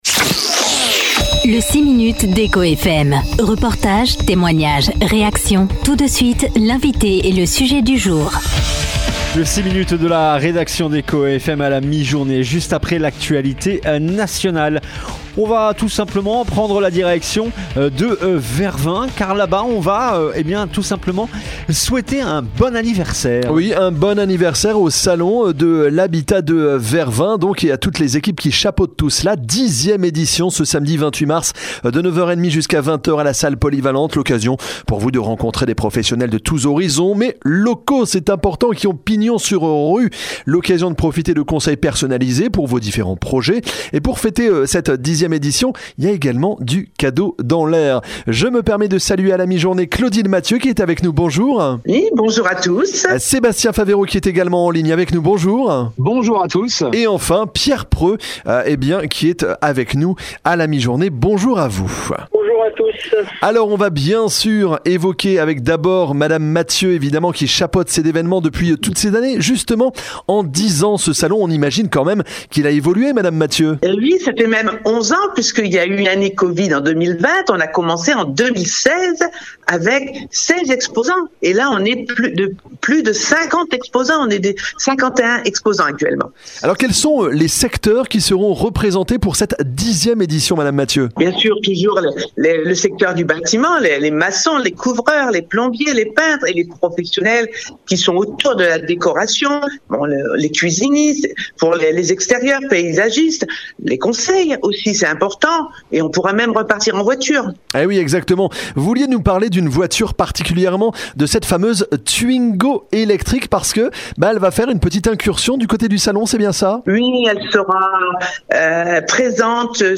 3. Le 6 minutes ECHO FM